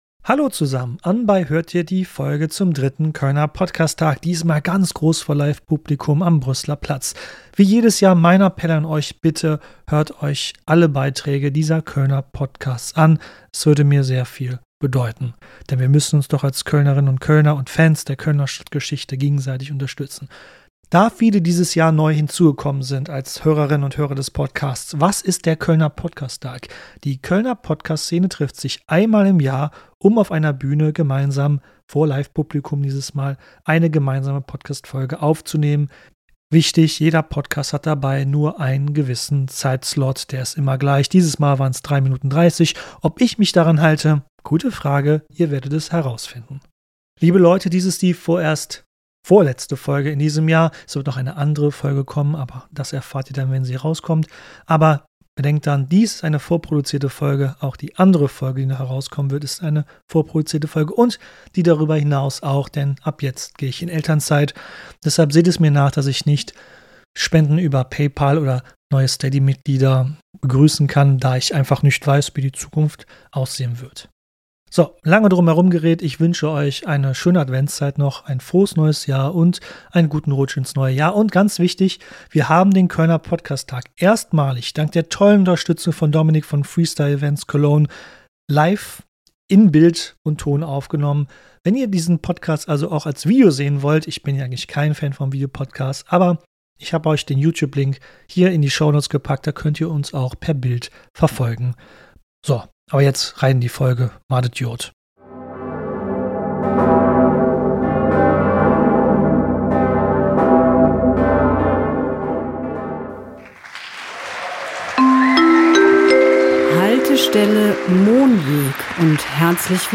Wieder einmal haben die kölschen Podcaster eine gemeinsame Folge produziert.
Aufgenommen live und voller Atmosphäre macht dieser Podcast hörbar, warum Köln so ist, wie es ist: vielfältig, widersprüchlich, lebendig. Neun Podcasts – eine Straße – ein Abend, der Köln erzählt.